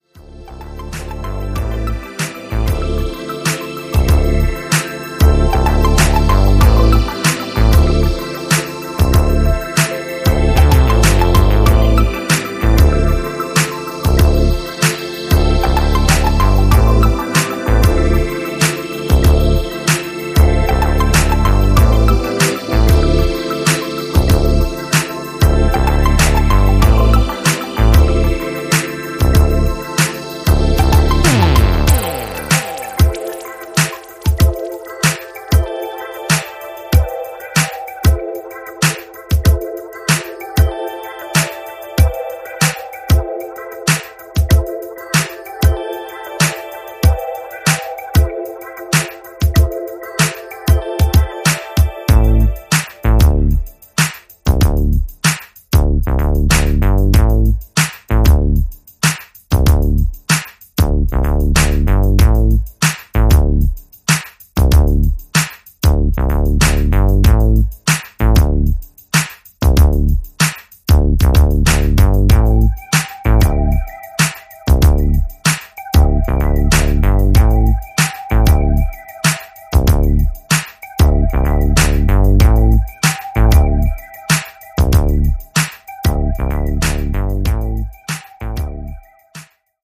メロウでシルキーなシンセ・ファンク/ブギーをダウンテンポで展開していく